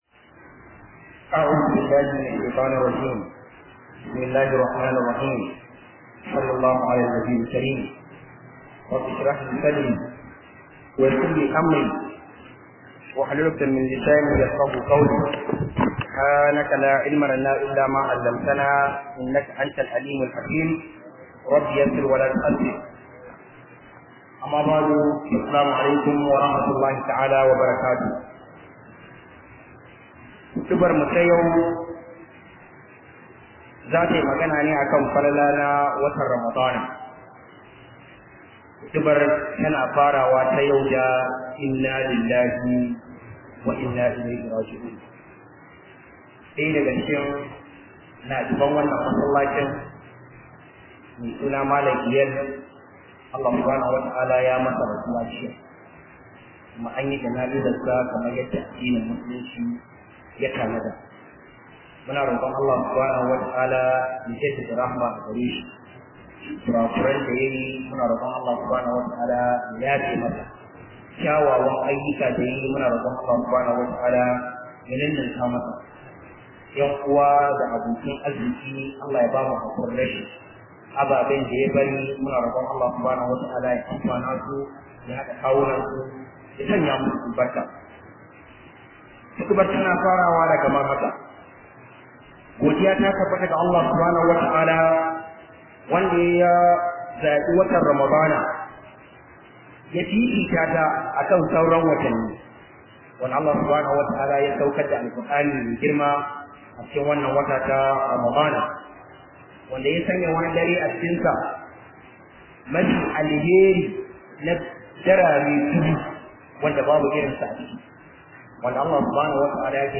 017 khudubah kan falalan Ramadan 2019.mp3